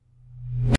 反向立管" 立管19
描述：记录会议的不同影响逆转，以建立动画紧张。
Tag: 立管 冲突 reversic 冲击